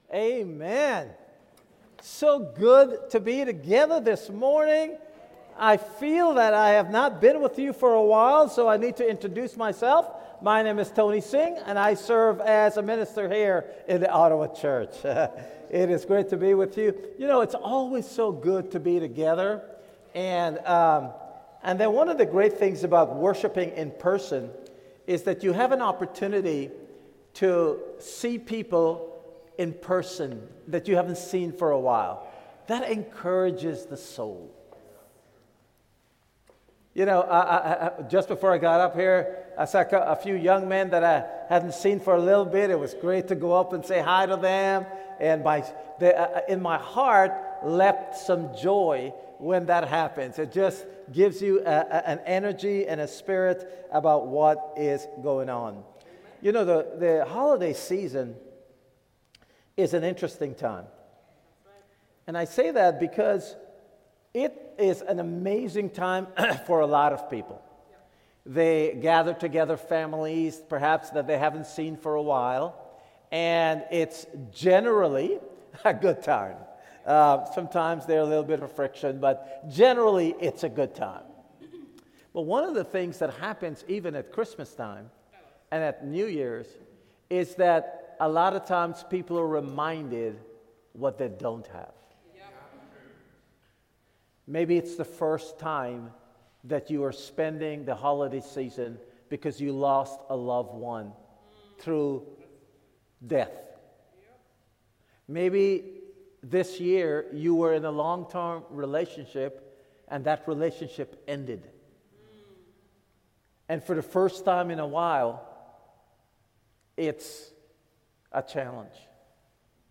Ottawa Church Of Christ Sermon Podcast